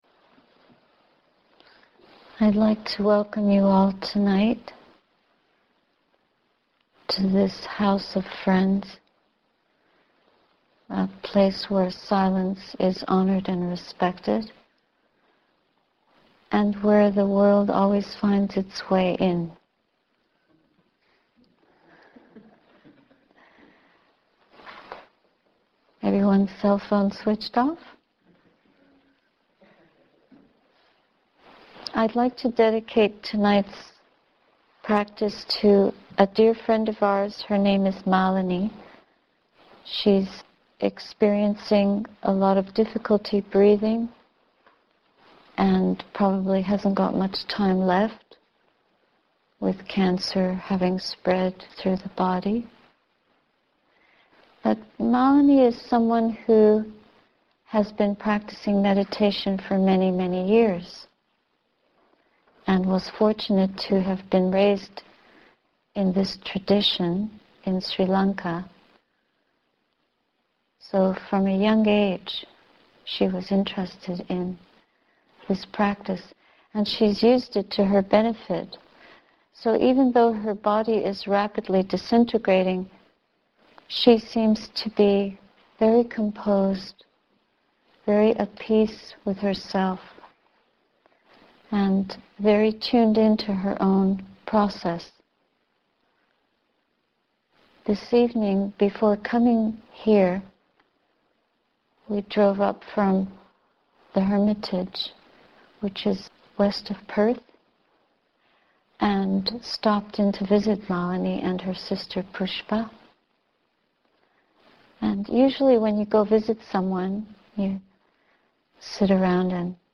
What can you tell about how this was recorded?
Ottawa Buddhist Society, Quaker House, 2009